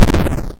snd_death.ogg